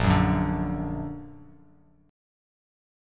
Bb+ Chord
Listen to Bb+ strummed